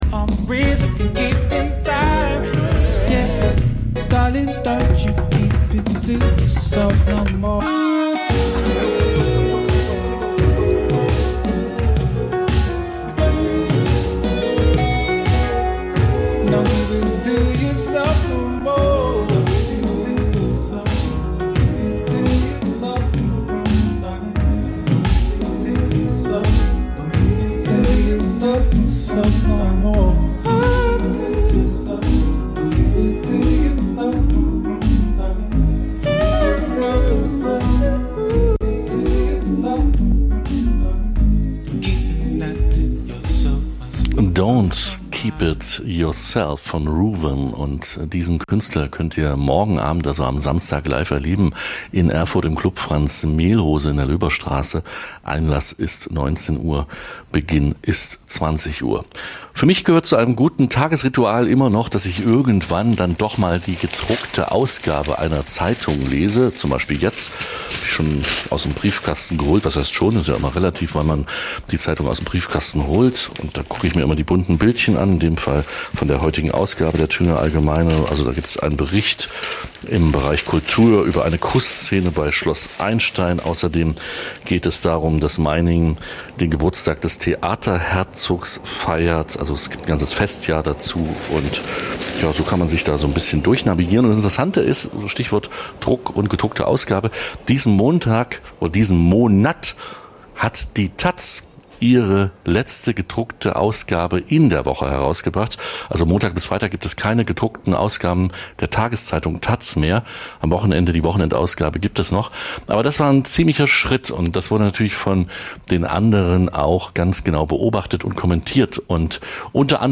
Das tagesaktuelle Livemagazin sendet ab 2024 montags bis freitags 9-11 Uhr. Jeden Tag von anderen Moderator:innen und thematisch abwechslungsreich best�ckt.